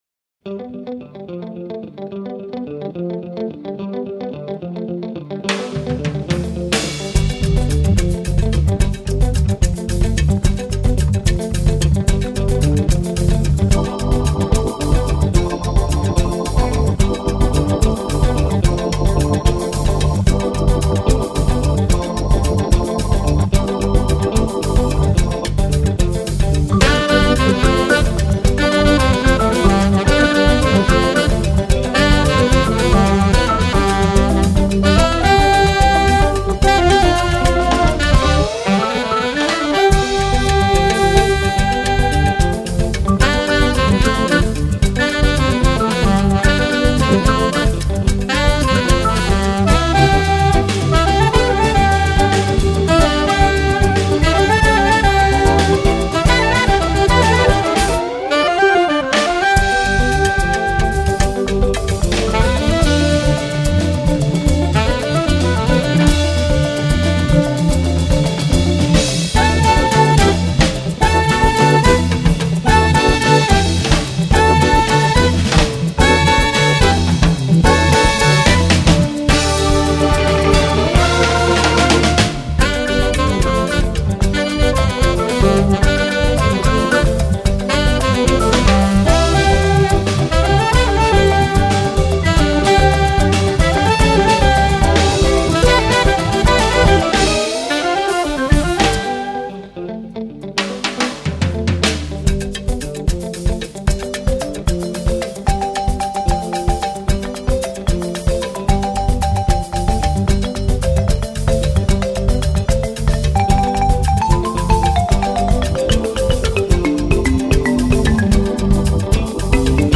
hammond organ B3
alto & soprano sax, flute
guitar
balafon
drums